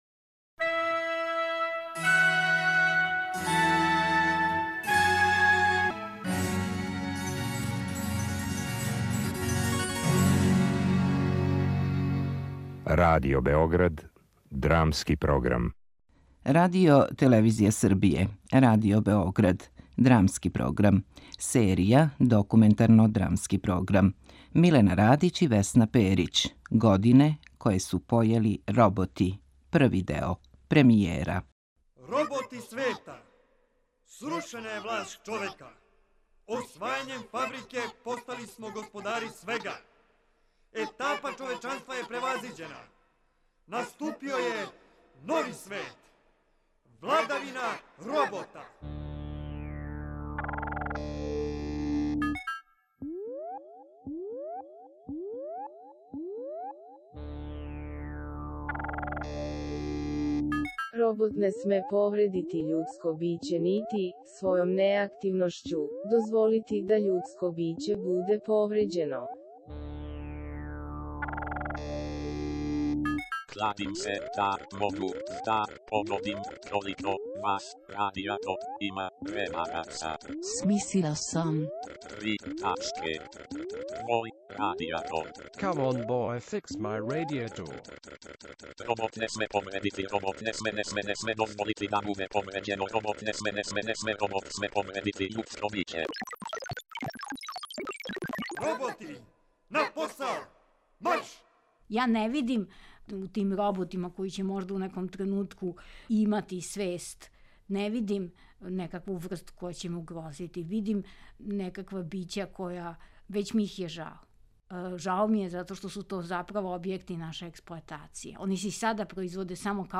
Документарно-драмски програм (премијера)
У емисији су коришћени инсерти из истоимене радио-драме из 1990. године у режији